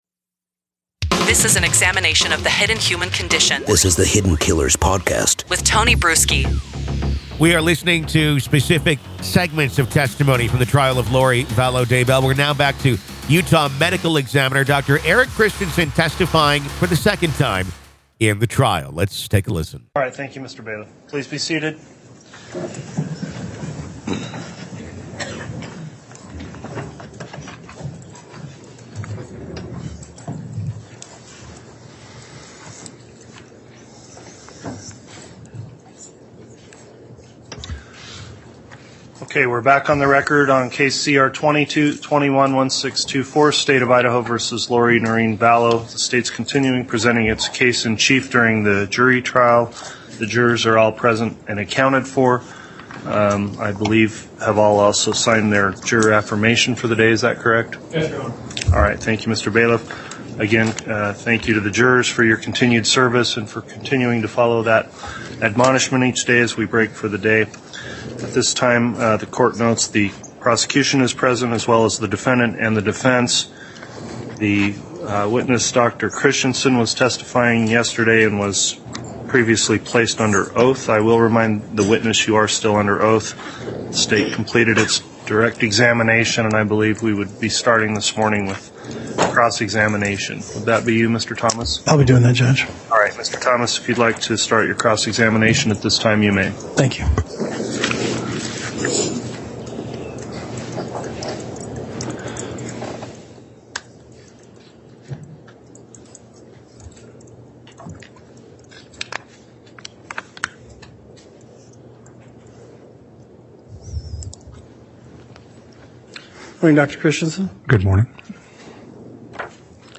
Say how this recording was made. Full Courtroom Coverage